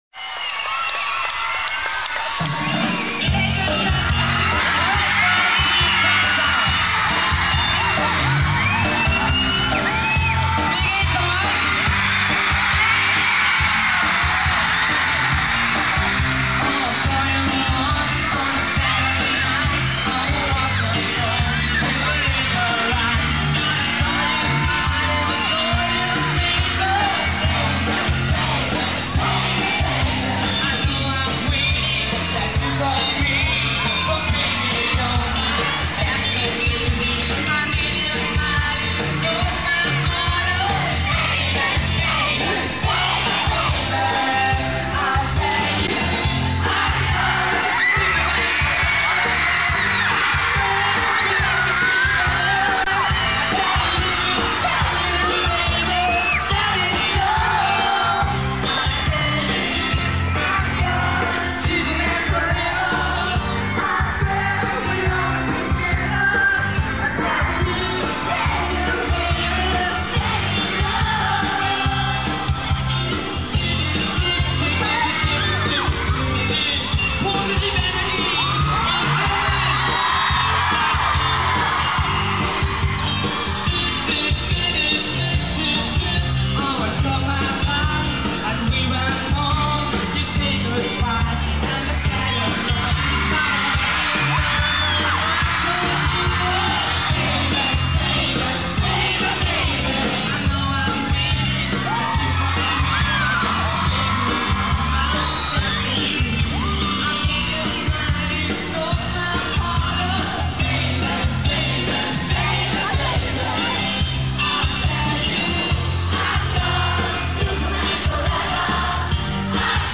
Fing der Saal an zu toben.